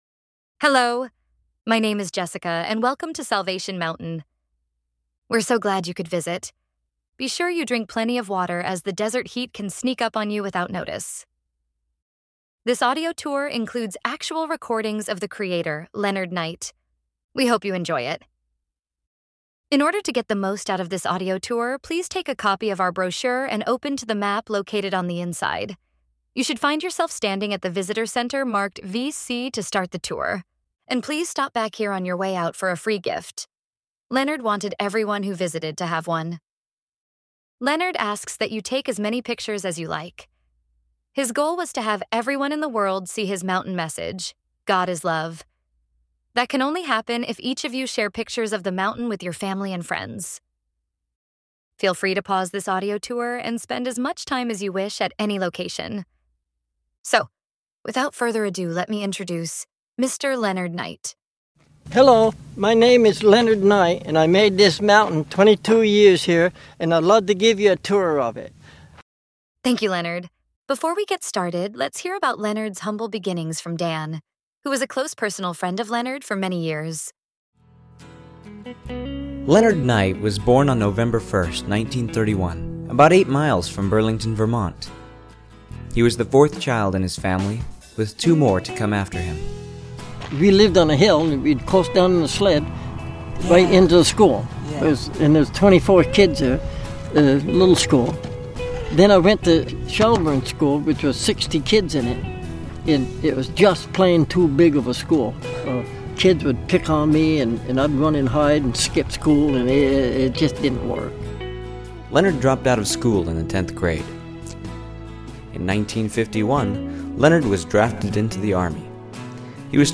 Salvation Mountain Audio Tour